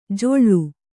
♪ joḷḷu